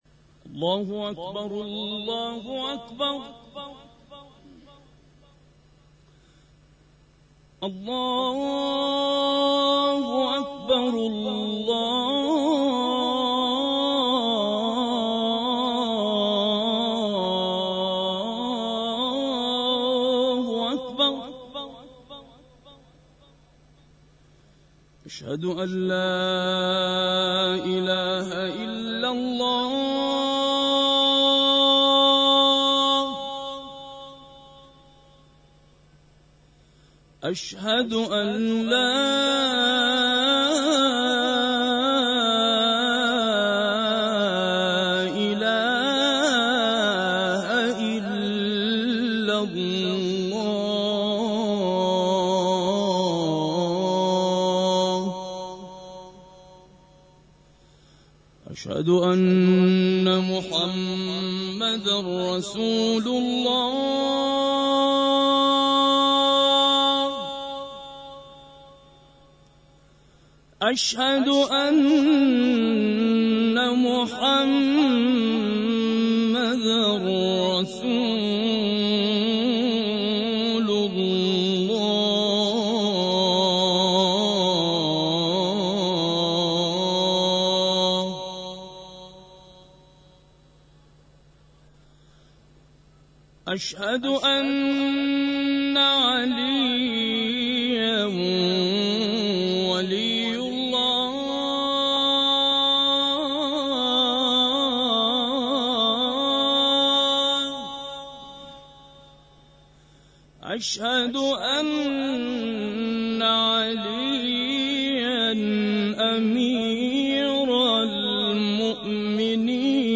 اذان
في حرم السيدة فاطمة المعصومة (ع)